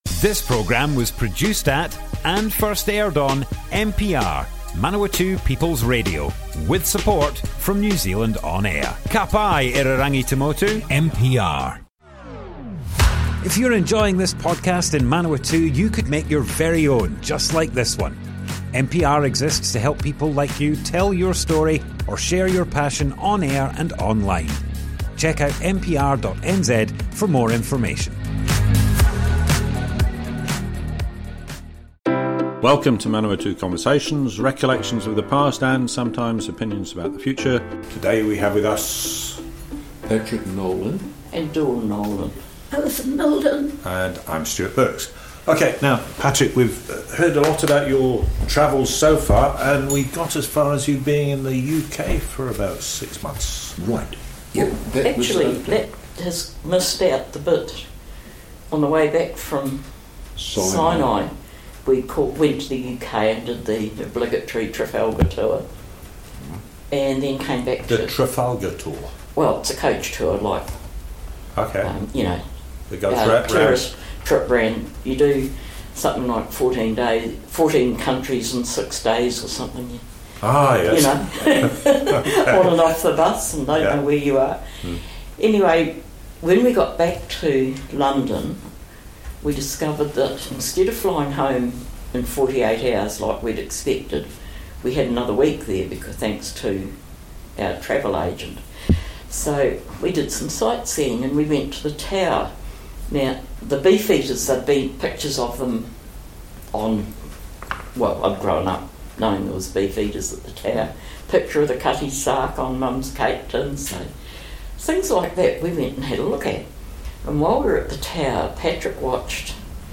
Manawatu Conversations More Info → Description Broadcast on Manawatu People's Radio, 19th July 2022, Part 3 of 5.
oral history